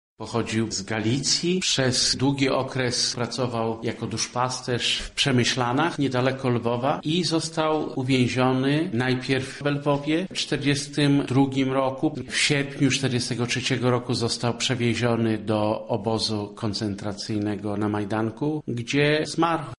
Historię błogosławionego przybliża ksiądz